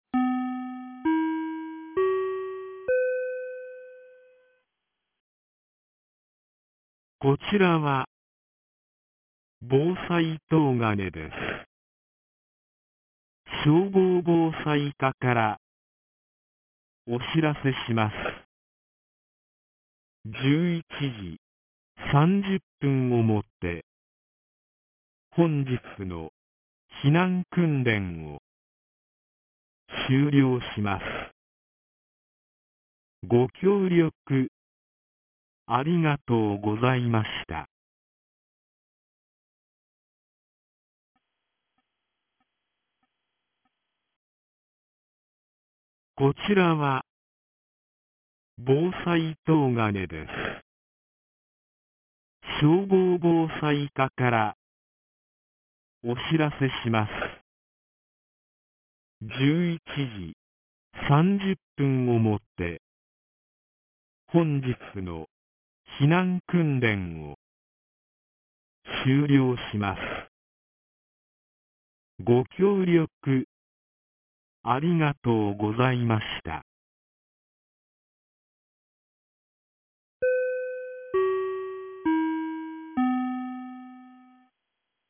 2025年11月01日 11時36分に、東金市より防災行政無線の放送を行いました。